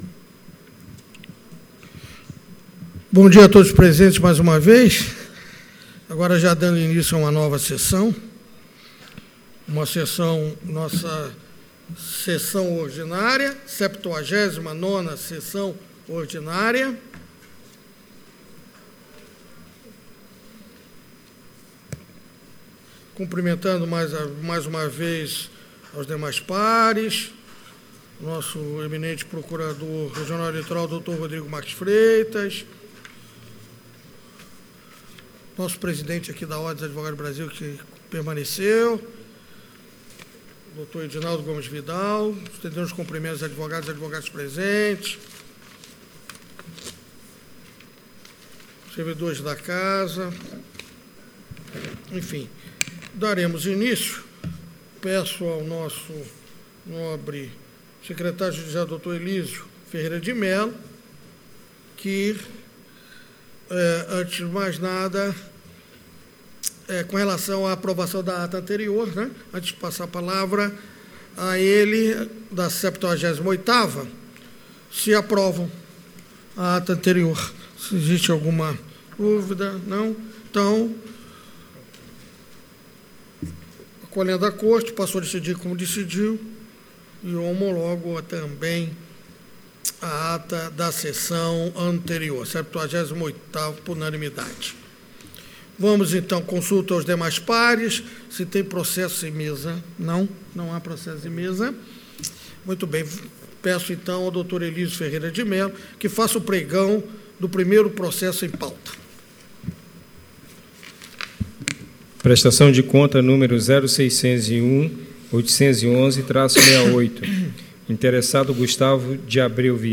Áudio da 79ª Sessão Ordinária de 18 de outubro de 2019.